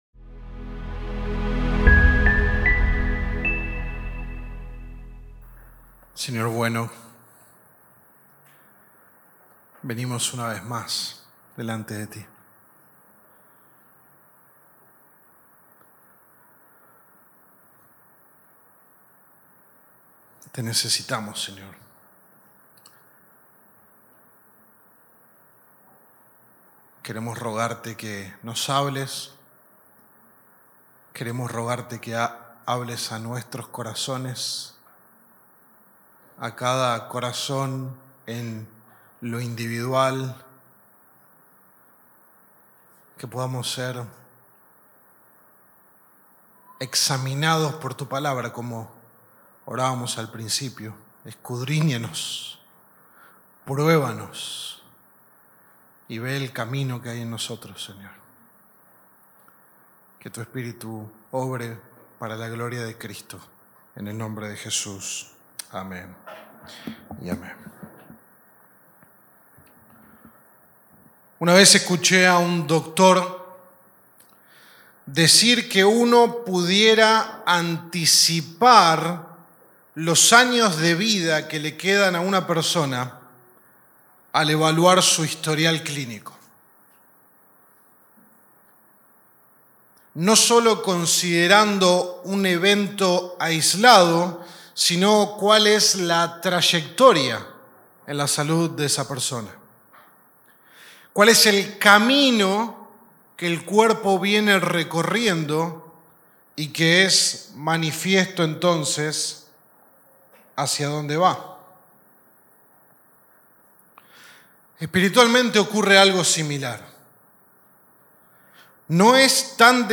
Sermón 1 de 8 en Delante de Dios